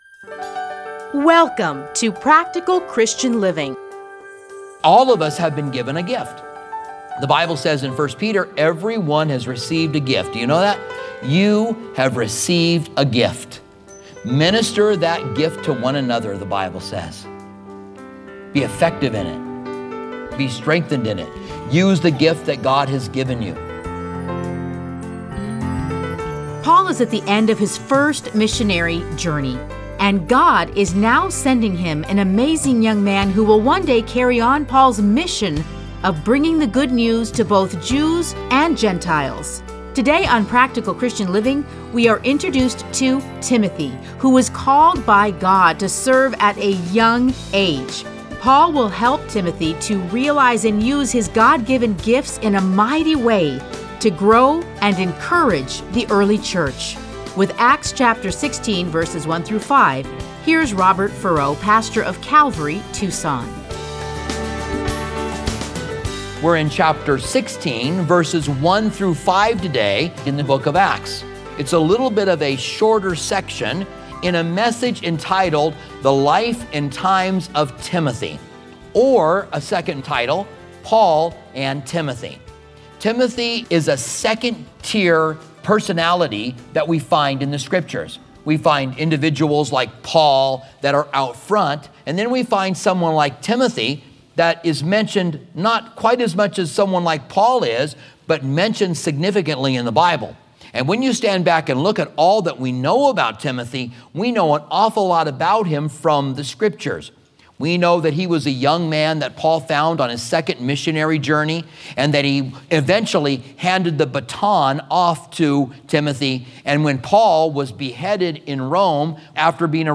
Listen to a teaching from Acts 16:1-5.